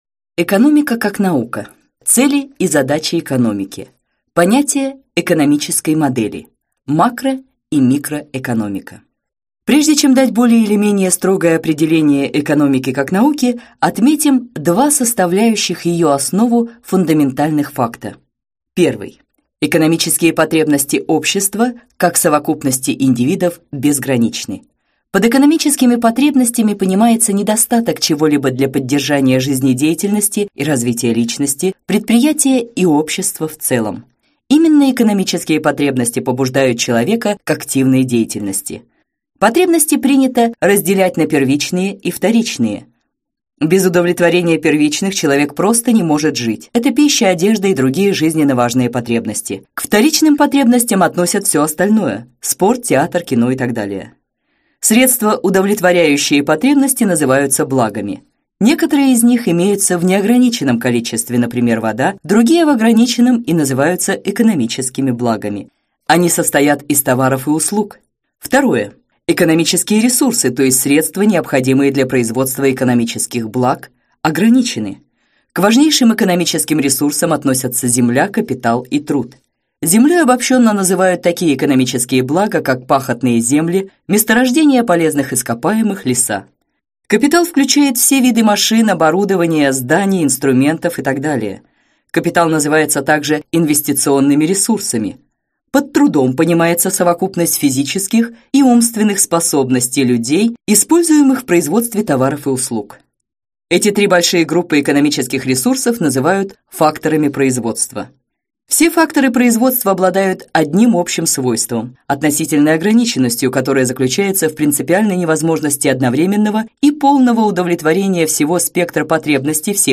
Аудиокнига Лекции по экономике | Библиотека аудиокниг